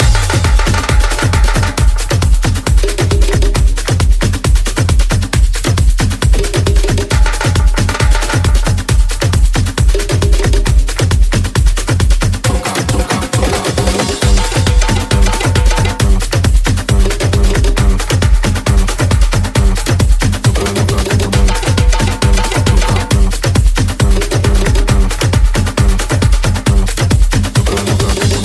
Genere: house,salsa,tribal,afro,tech,remix,hit